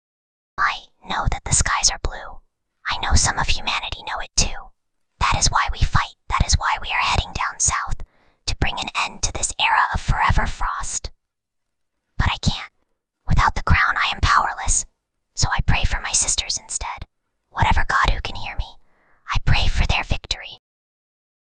Whispering_Girl_24.mp3